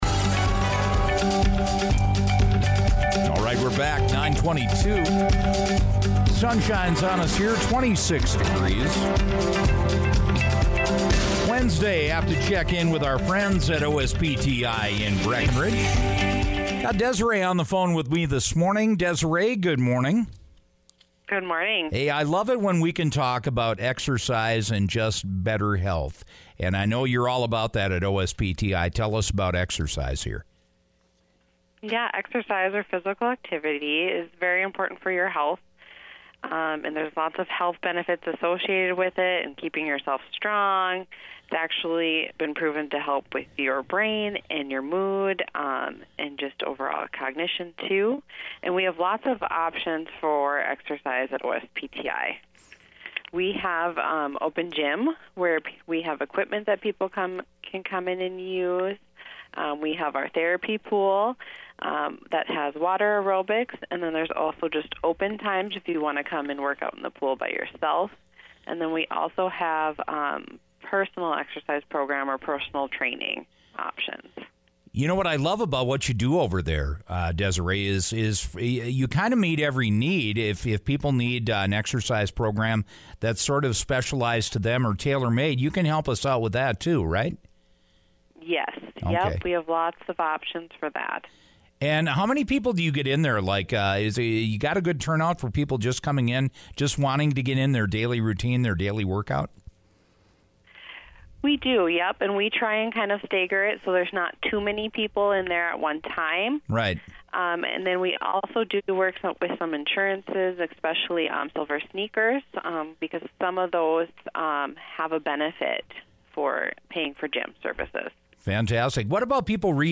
You can hear our podcast conversation below.